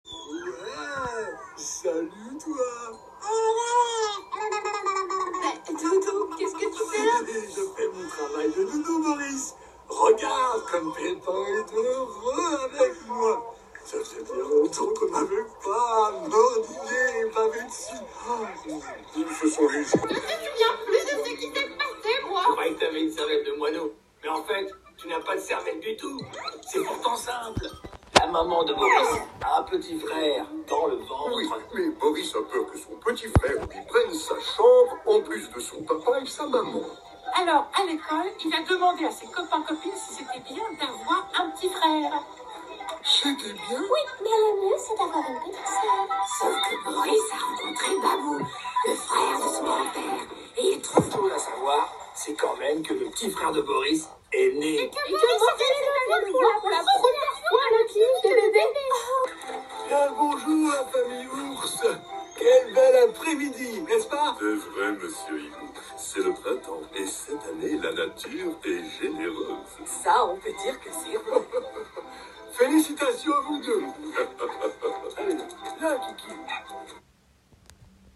Comédien
- Baryton